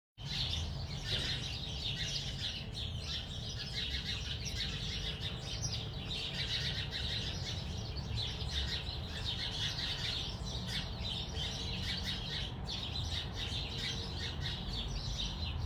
Une nuée de piailleurs jaunes occupe l’arbre riverain appelé  juage, par les latinos et leucaena_leucocephala par les latinistes, un arbre dont les feuilles ressemblent à des fougères et les fruits à des haricots mangetout.
Éminemment sympathique à 7h30, vaguement casse-c… arrivé 20h les canaris… Tiens juste 15 secondes.